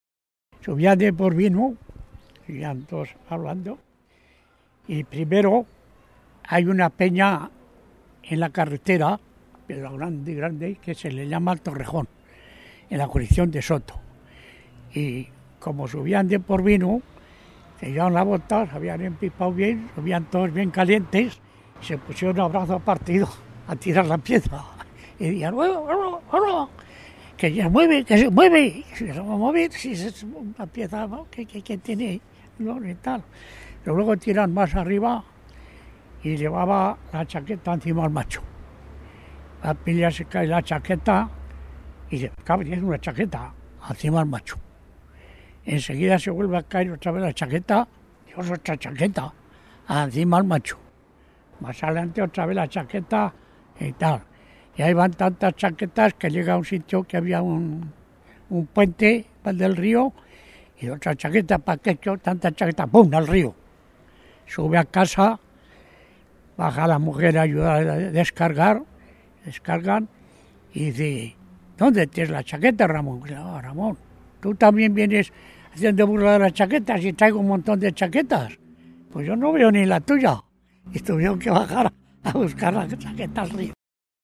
Clasificación: Cuentos, cultura del vino
Lugar y fecha de recopilación: Logroño, 19 de septiembre de 2002